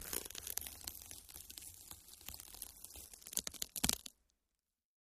ho_fleshtear_skull_01_hpx
Human flesh being ripped and torn from skull. Mutilation, Body Dismemberment, Gore Tear, Flesh